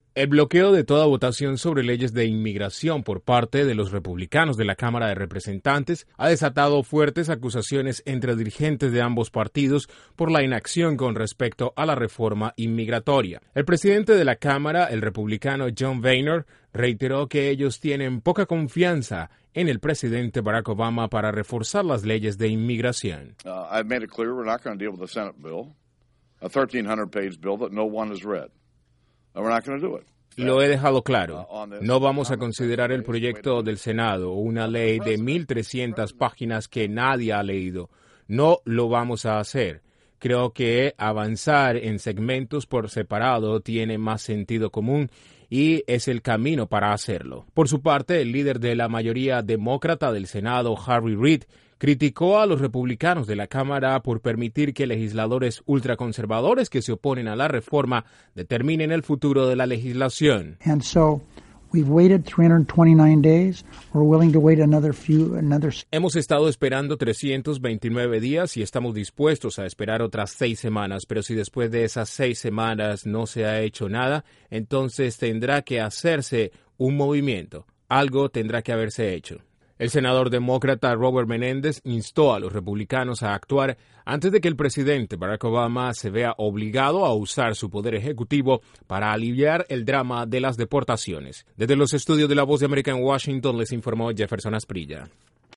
Sigue la batalla en el congreso de EEUU por la reforma de inmigración, los demócratas le enviaron una fuerte advertencia a los republicanos que se niegan a aprobar el proyecto de ley. Desde la Voz de América en Washington informa